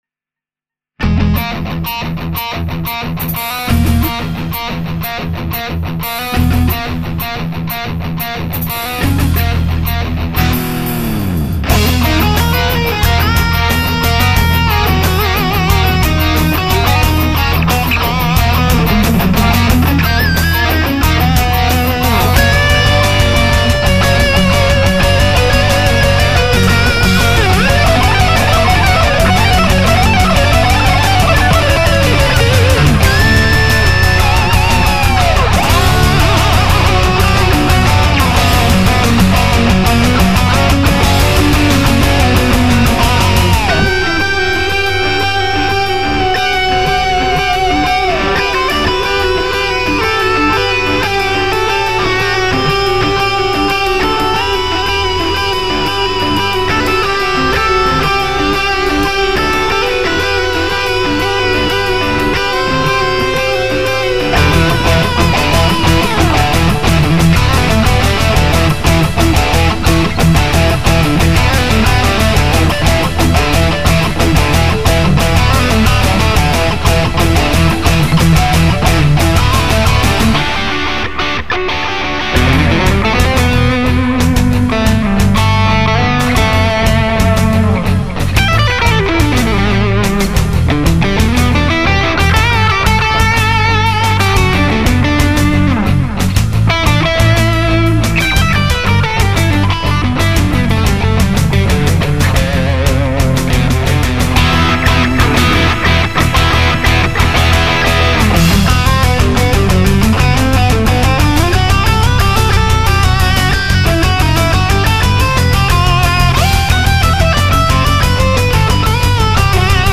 Vos Compos Metal progressif
Voilà un autre petit délire avec des nuages de prog et un éclat néo classique.
J'ai craqué et mis un petit délire shredien en plein milieux. C'est plein de notes, certaines un peu cracra mais ca m'a fait tellement plaisir...
Pas mal du tout cette petite demo, c peche surtout du cote de la batterie pour le son mais bon peut-on attendre beaucoup mieux d'une machine que d'un homme pour le feeling...???